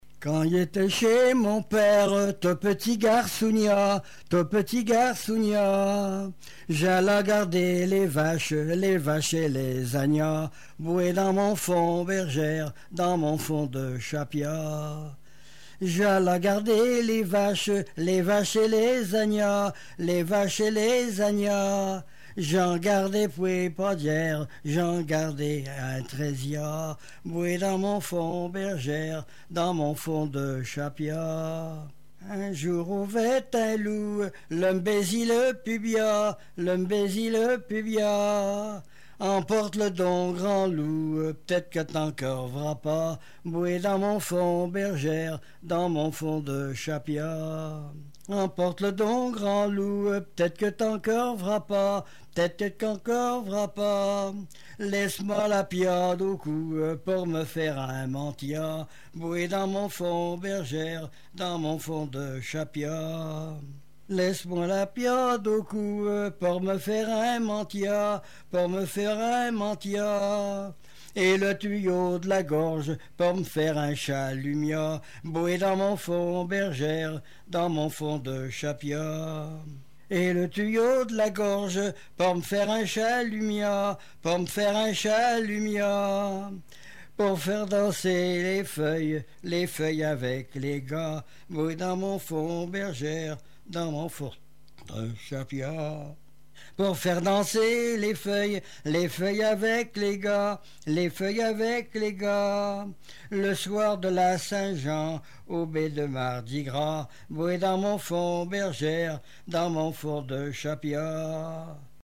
Langue Patois local
Genre laisse
Chansons et témoignages
Pièce musicale inédite